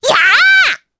Rephy-Vox_Attack4_jp.wav